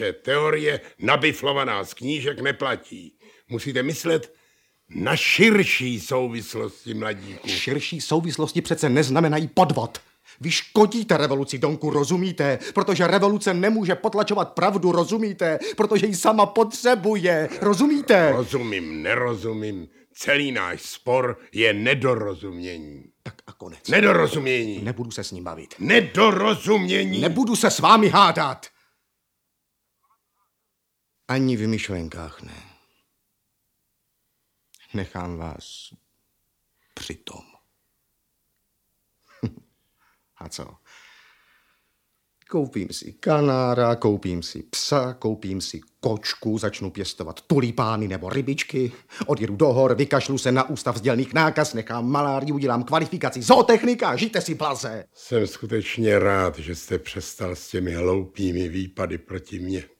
Audiobook
Audiobooks » Short Stories
Read: Zdeněk Štěpánek